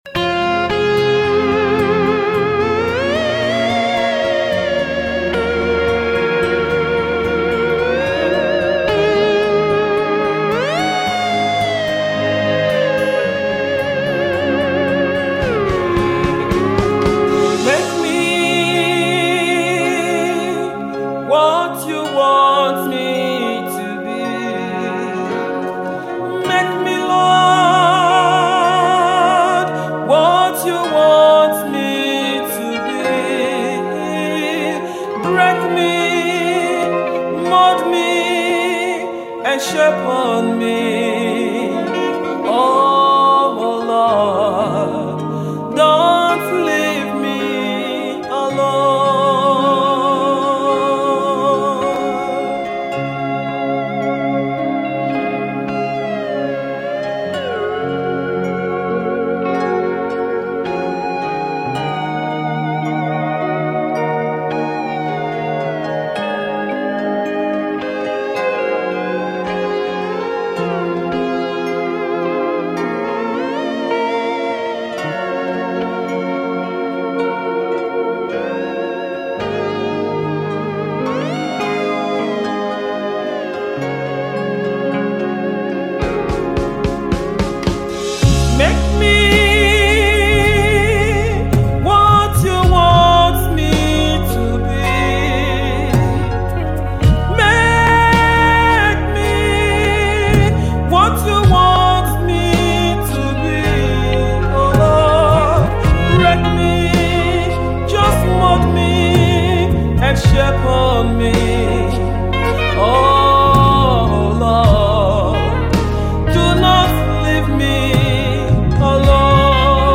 supplication tune